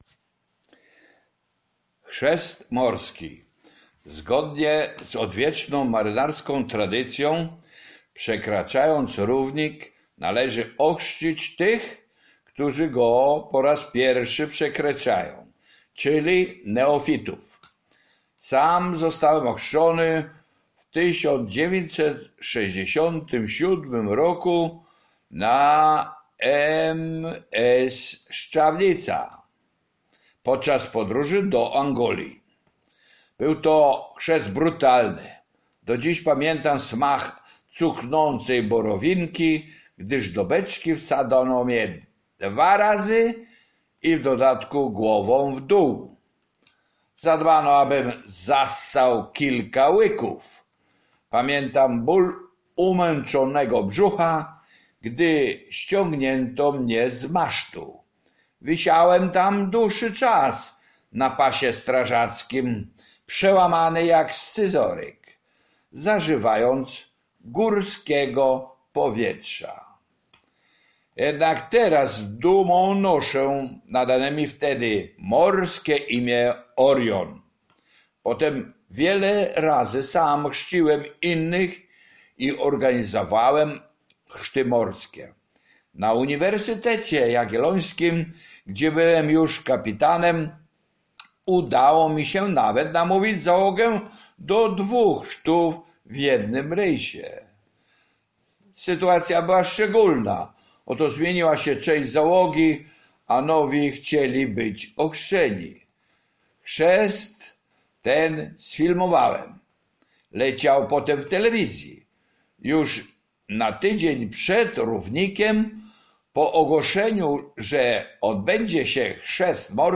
Życie marynarskie (audiobook). Rozdział 38 - Chrzest morski - Książnica Pomorska